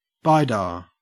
Ääntäminen
Synonyymit BiFi Ääntäminen GA : IPA : /ˈbaɪˌdɑɹ/ AU RP : IPA : /ˈbaɪ.dɑː/ Haettu sana löytyi näillä lähdekielillä: englanti Käännöksiä ei löytynyt valitulle kohdekielelle.